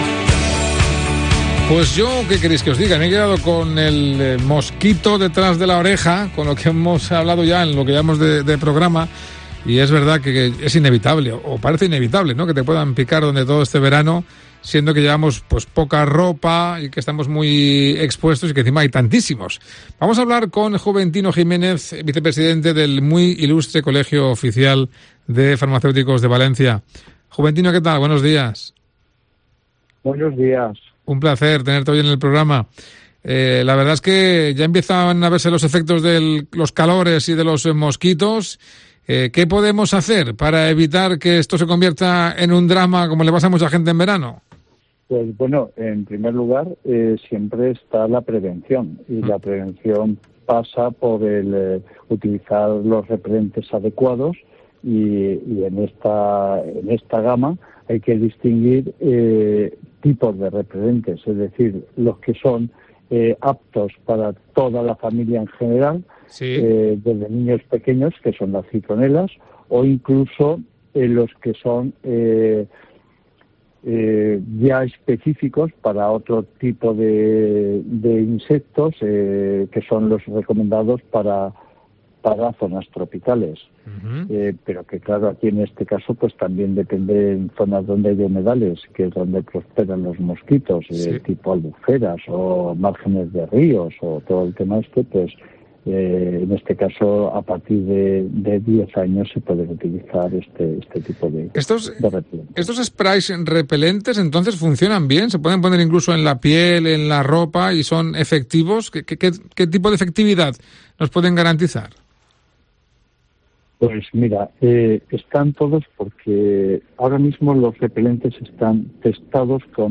Mediodía COPE MÁS Valencia | Entrevista sobre cómo usar correctamente el repelente de mosquitos